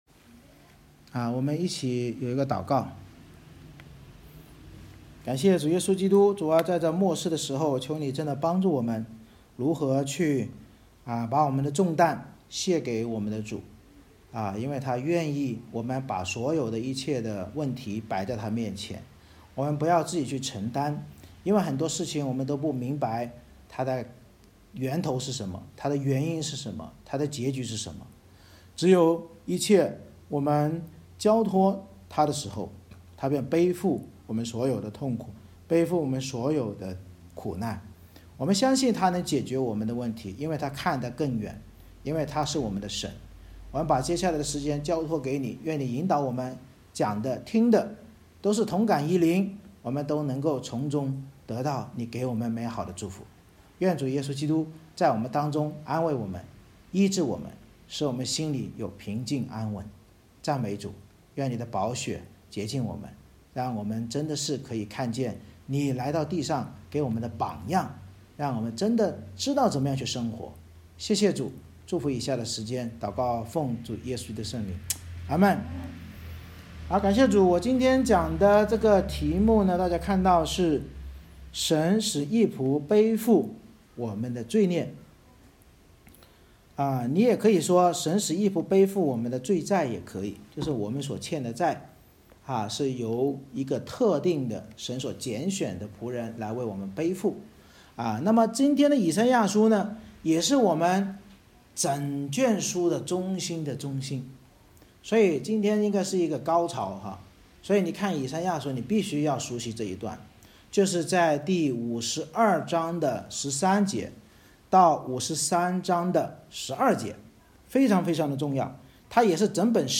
以赛亚书 Isaiah52:13-53:12 Service Type: 主日崇拜 神藉着先知预言义仆耶稣基督遵行祂的旨意来到世上，背负我们人类所有罪债而受苦受死，使因信称义之人能得到完全的平安和医治。